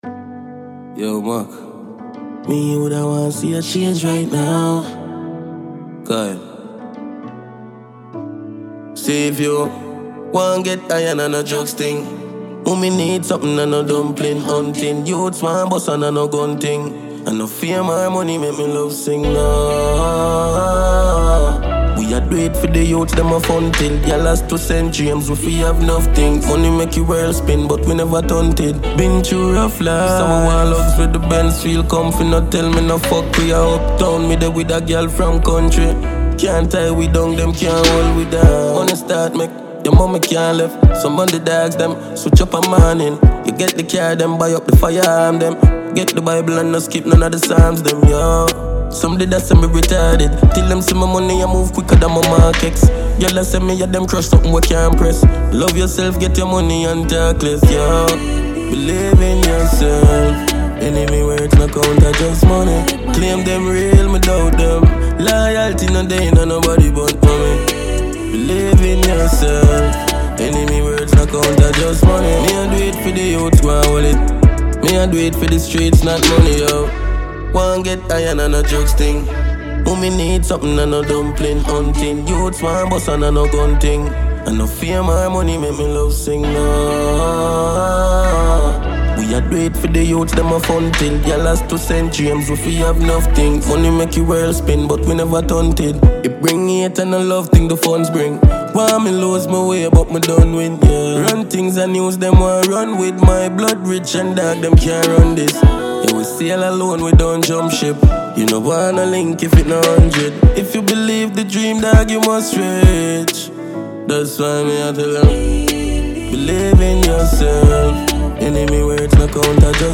Renowned Jamaican dancehall superstar